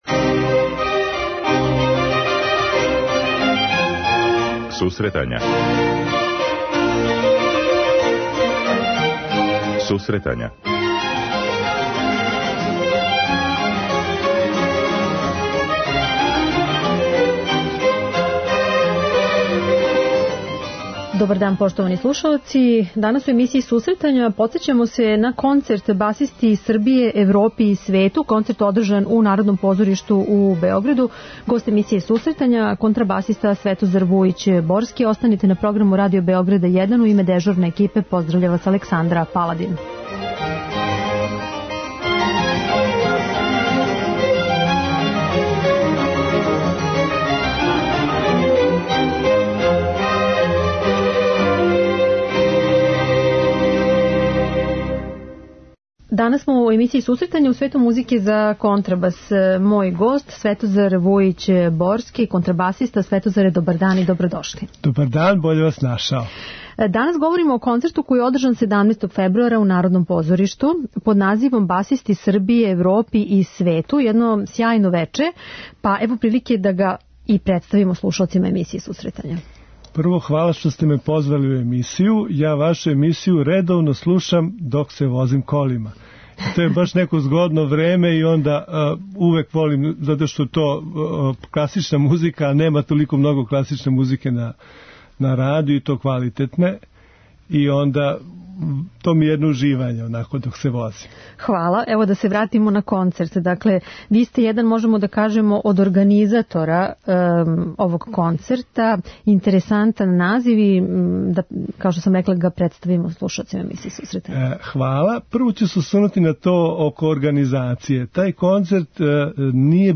преузми : 10.77 MB Сусретања Autor: Музичка редакција Емисија за оне који воле уметничку музику.